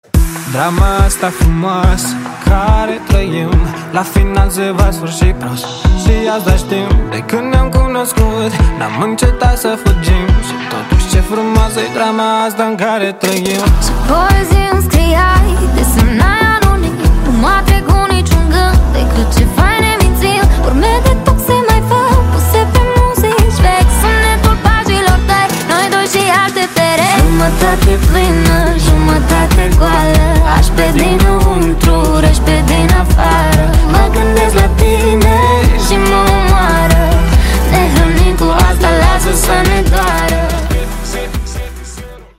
поп
красивый мужской голос
dance
дуэт
медленные
красивый женский голос
романтические